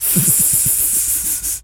pgs/Assets/Audio/Animal_Impersonations/snake_hiss_03.wav at master
snake_hiss_03.wav